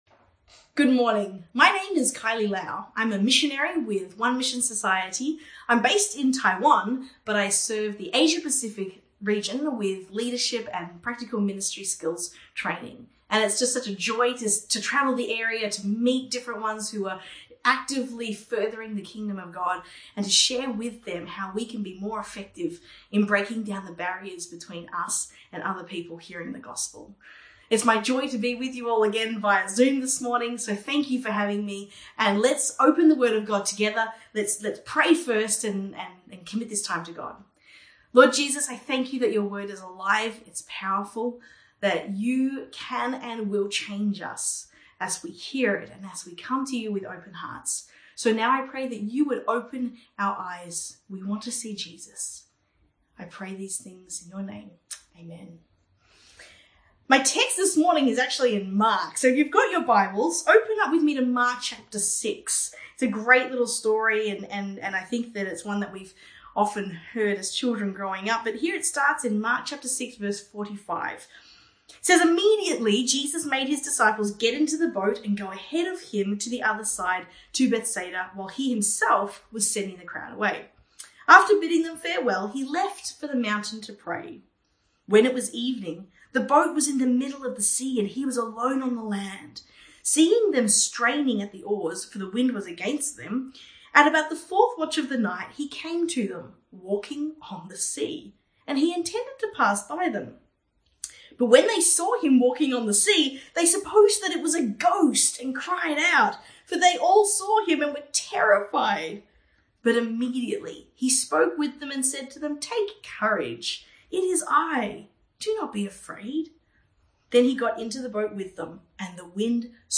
English Sunday Message